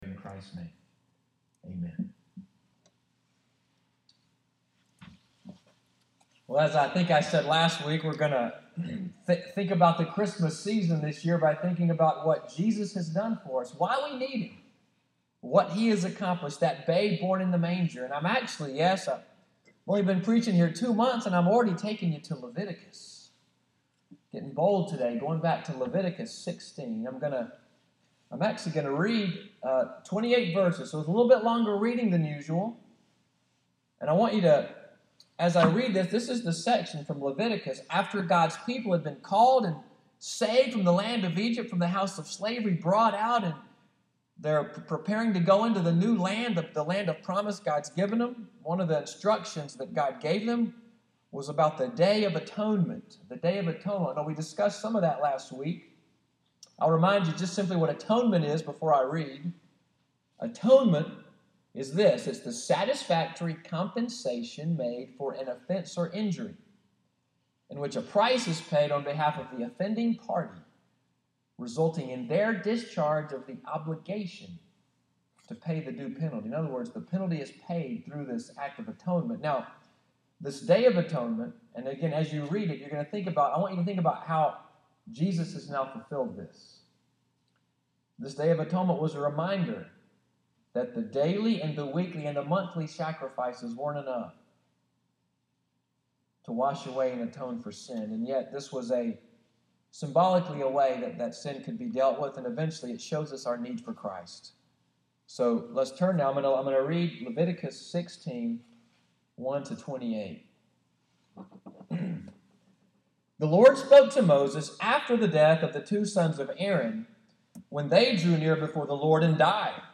Sermon on Leviticus 16 preached at New Covenant Presbyterian Church in Selma, Alabama on Dec. 6, 2015.